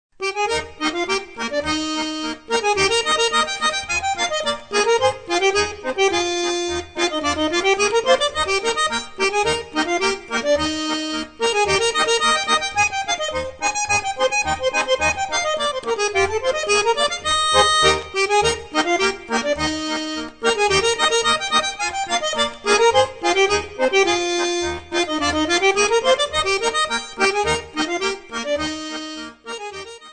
Stücke der originalen Volksmusik berücksichtigt.